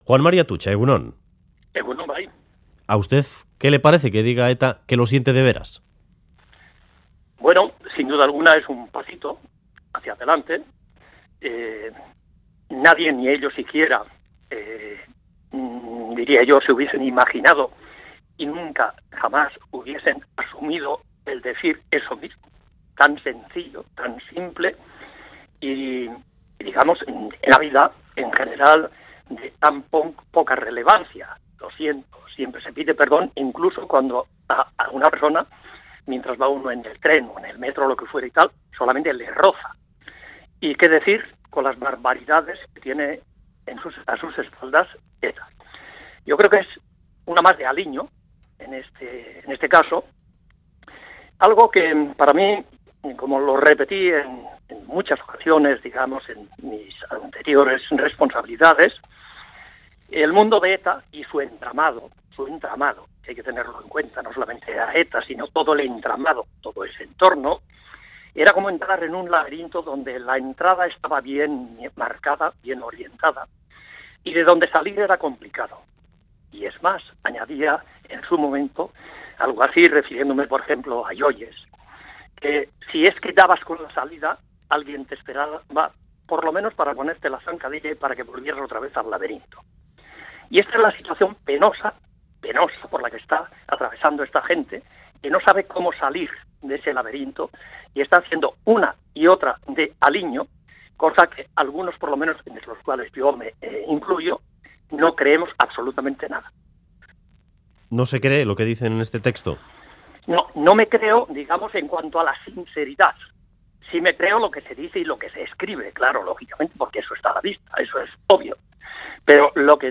Audio: Audio: Entrevista a Juan María Atutxa tras el comunicado de ETA